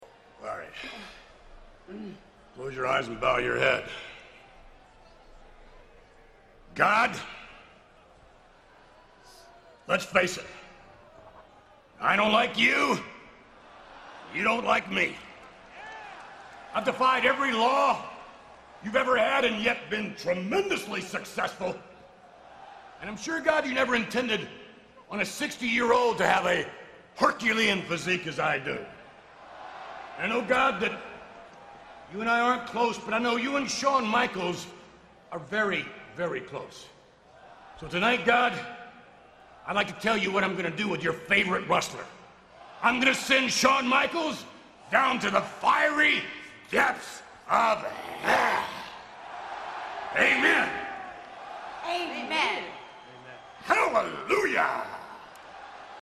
a promo on God.